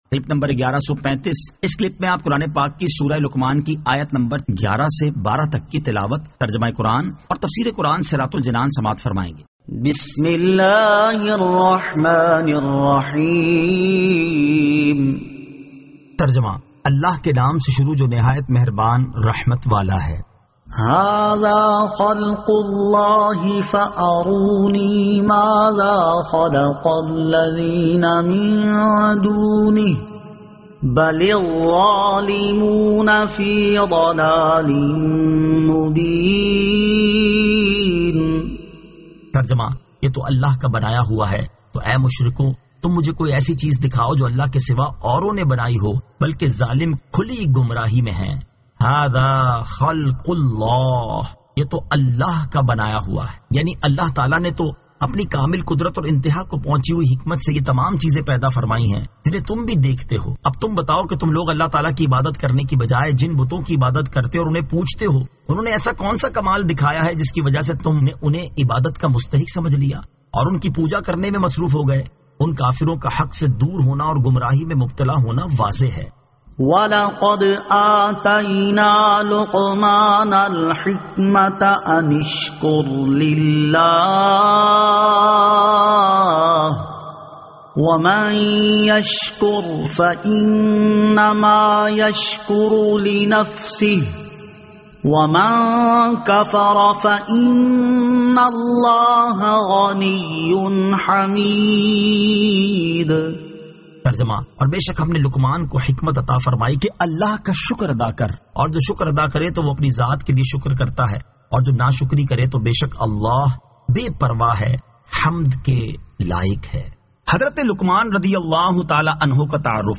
Surah Luqman 11 To 12 Tilawat , Tarjama , Tafseer